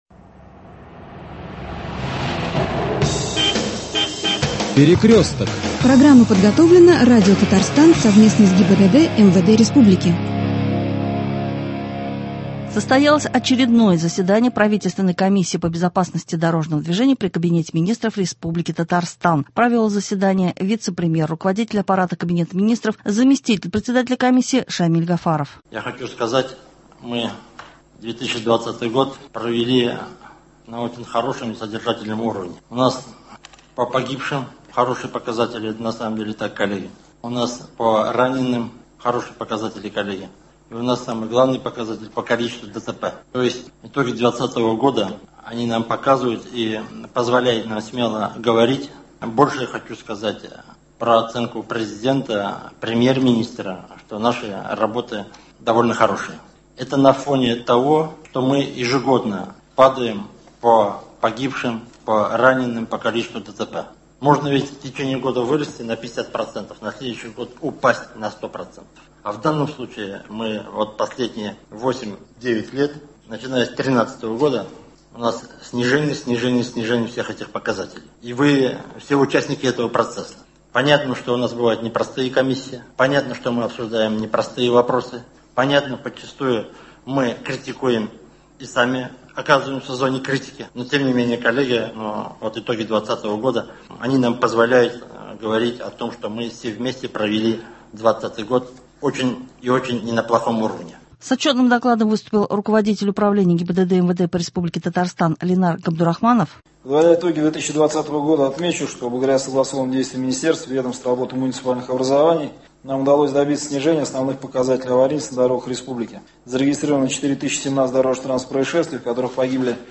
Репортаж с заседания правительственной комиссии по безопасности дорожного движения при КМ РТ.